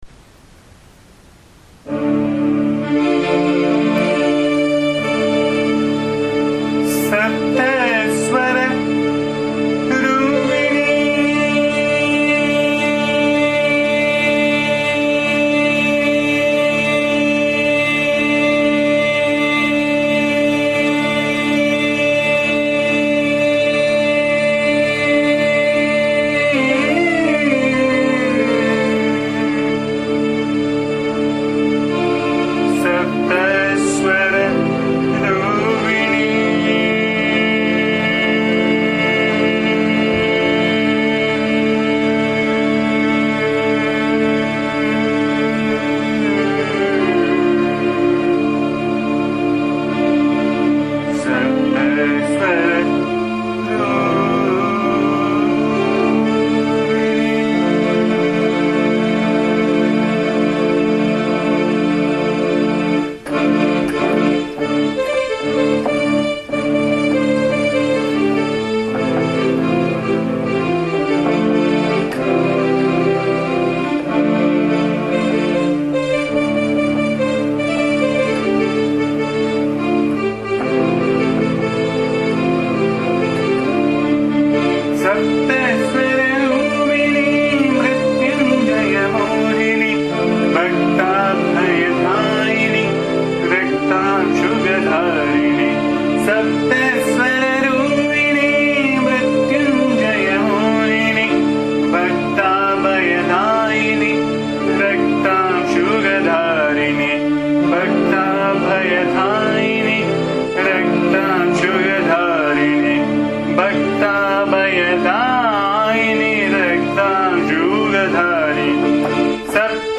AMMA's bhajan song View previous songs
very simple yet elegant song set in Raga Shuddha Dhanyaashi